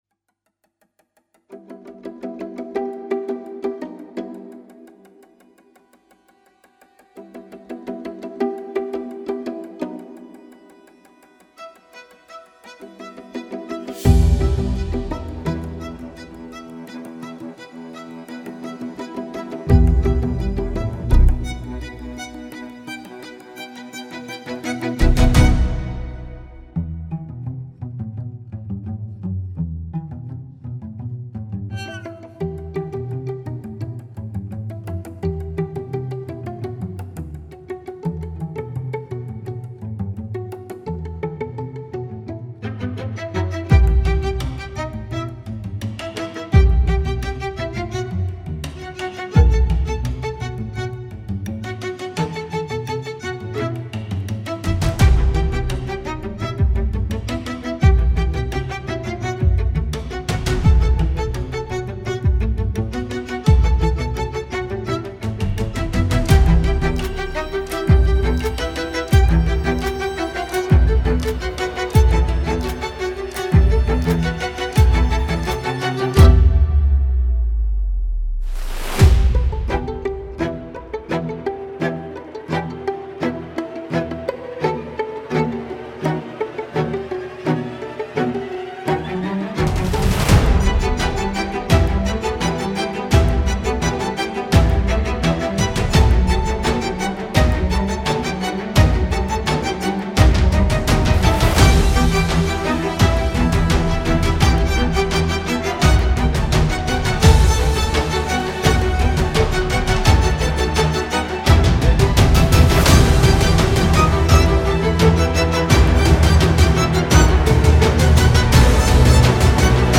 موسیقی بی کلام آرامش‌بخش